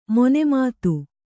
♪ mone mātu